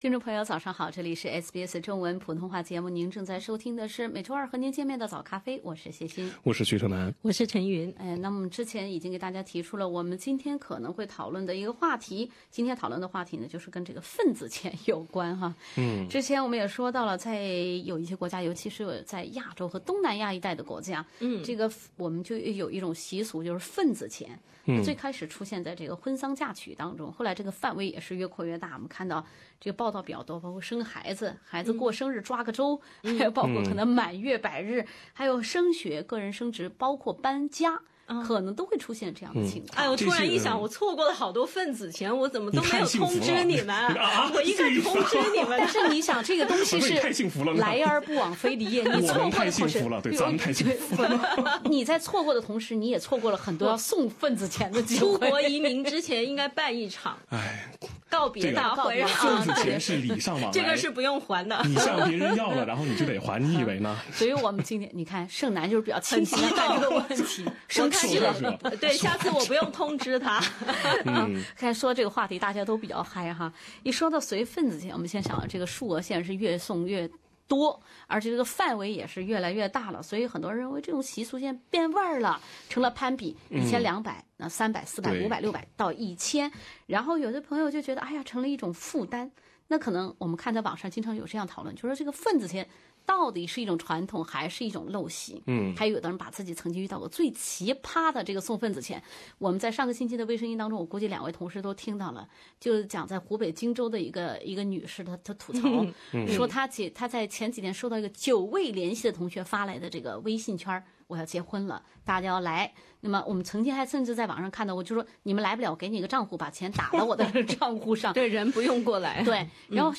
本周的“往期回顾“选择了SBS主持人讨论”份子钱“。听听主持人们经历过哪些让人哭笑不得的”份子钱“事件，听众又有什么好建议吧。